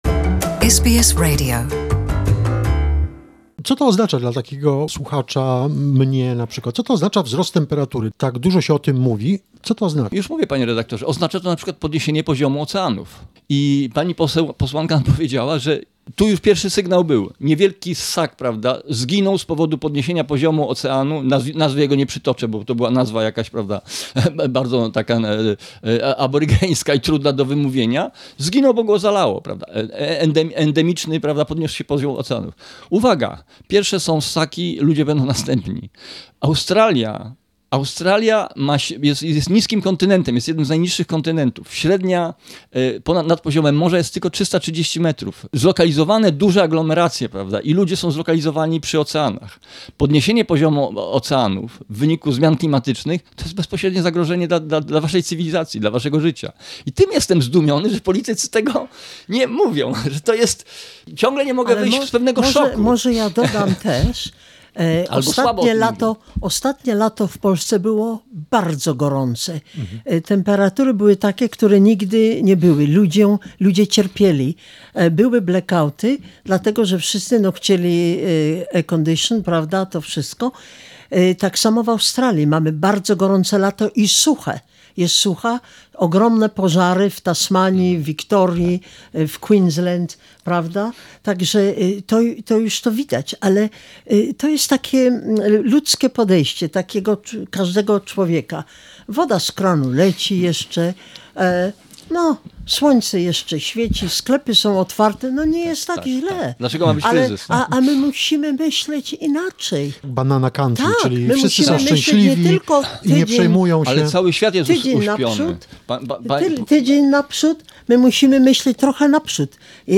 Part two of conversation with Radoslaw Gawlik, ecologist, politician, co-founder of the Green party in Poland, president of the Ecological Association EKO-UNIA.